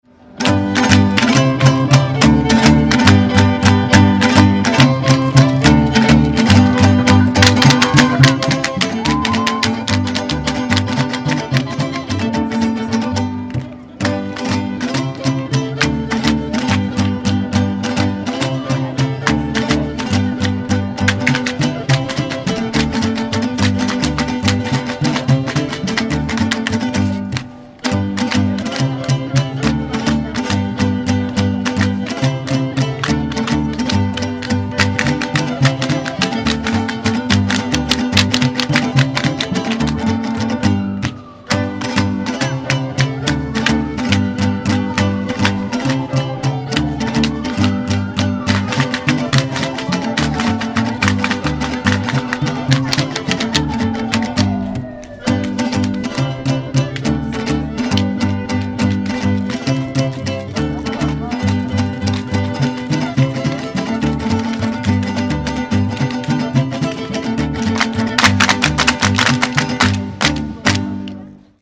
La Danza de los Viejitos es una espectacular danza del folclor mexicano originaria del estado de Michoacán, o más exactamente, de la región purépecha. Es una danza que celebra la vida y la juventud de espíritu, sin importar la edad.
Los danzantes visten de blanco, y sobre la vestimenta, tienen un sarape que les cubre el cuerpo, un sombrero con cintas de colores colgando en todo el contorno, y unas sandalias de madera especiales (en una de las fotos se aprecian), cuyo chasquido con el suelo hace ese peculiar ruido al son de la música.